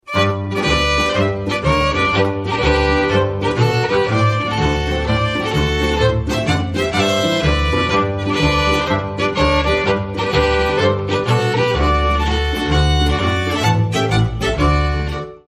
Volkstänze aus Niederösterreich